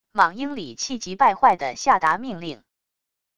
莽应里气急败坏的下达命令wav音频
莽应里气急败坏的下达命令wav音频生成系统WAV Audio Player